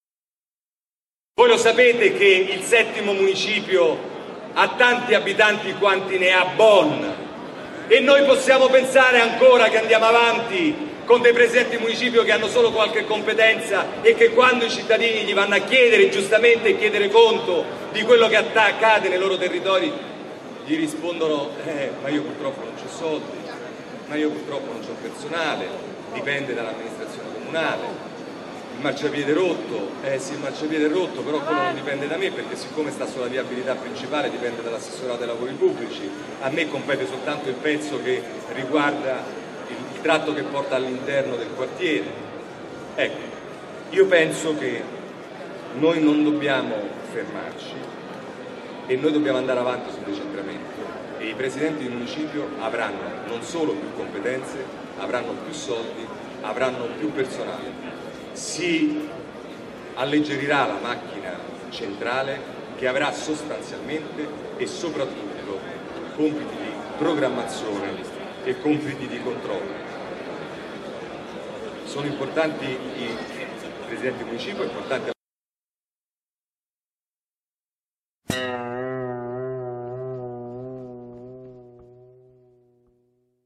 Inaugurazione "La Stazione di Giachetti", Roma 14 aprile 2016 Scalo di San Lorenzo.
Intervista a Roberto Giachetti sulla lettera del Comitato di Radicali per Giachetti Sindaco comparsa su l'Unita del 14/04/2016.